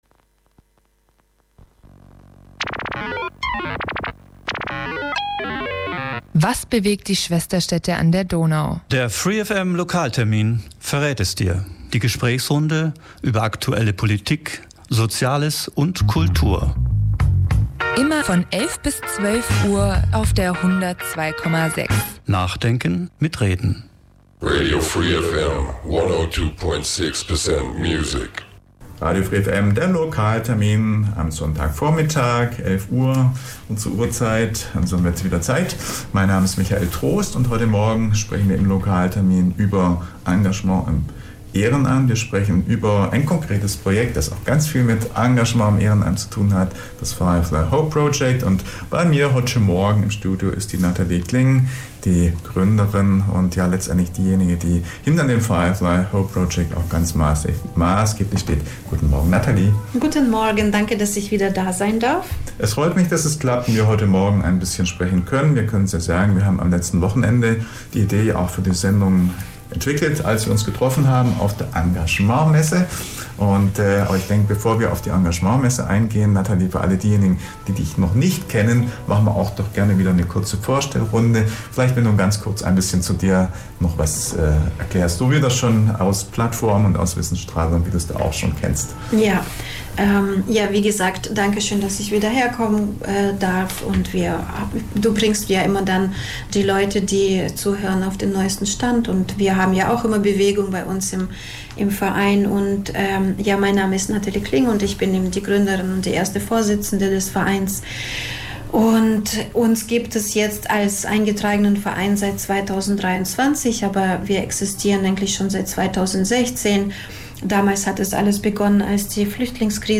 Der Fuß- und Radentscheid Ulm ist zum Gespräch bei uns im Studio. Es geht schwerpunktmäßig um den Fußverkehr in Ulm: Wo liegen die Probleme, was sind die Trends. Natürlich schweifen wir auch Richtung Radverkehr und blicken auf ÖPNV und Auto.